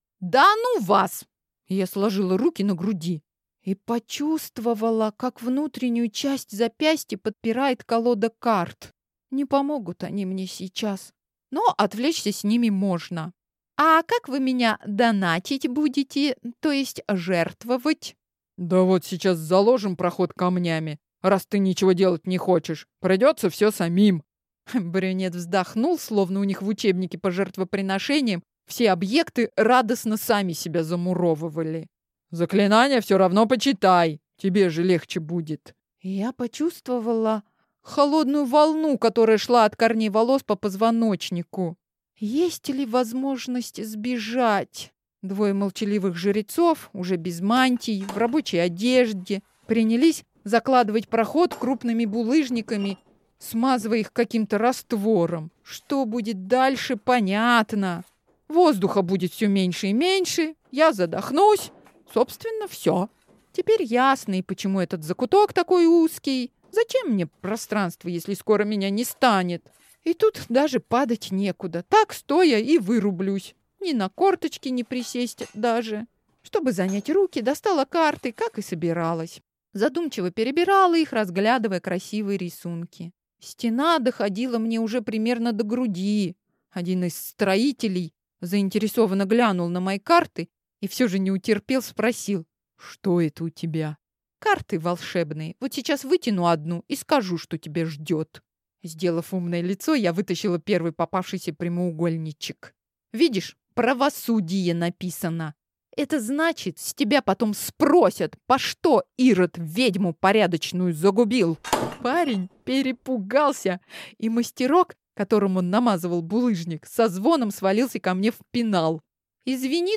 Аудиокнига На метле двоим не место, или Ведьма для жреца | Библиотека аудиокниг